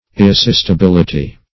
Irresistibility \Ir`re*sist`i*bil"i*ty\, n. [Cf. F.